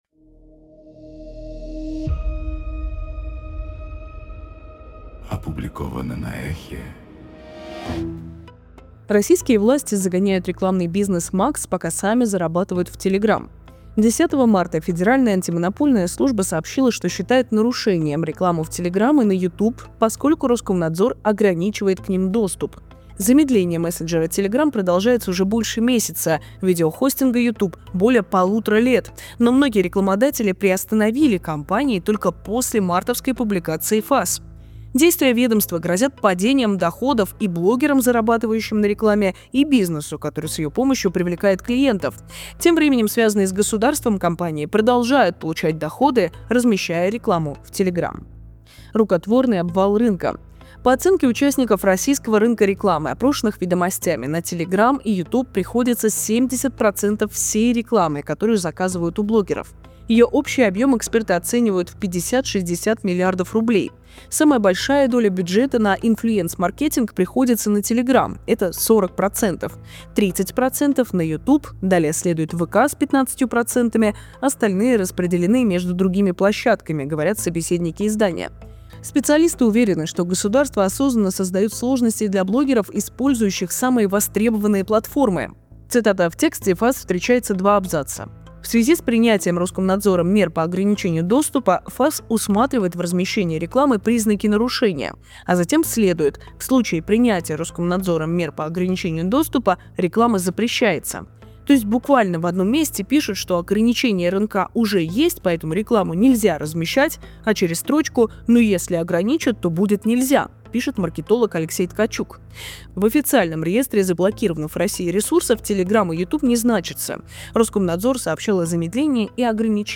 Читает